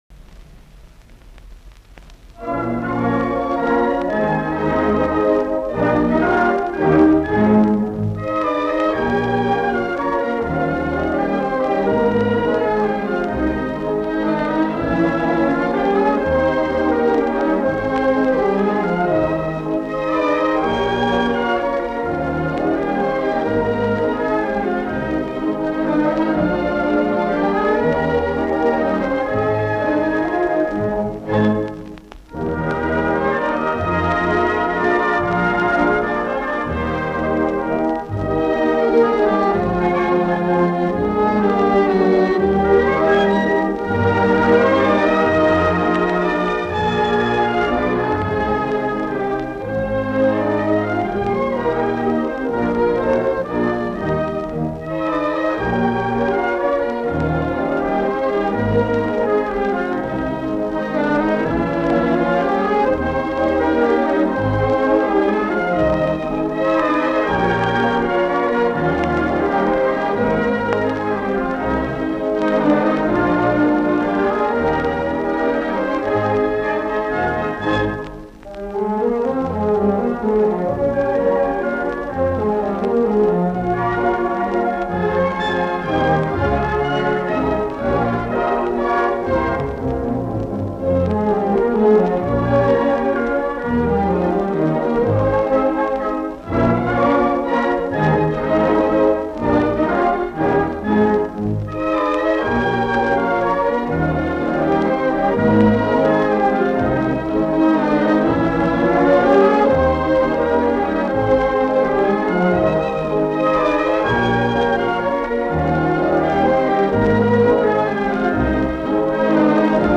бальный танец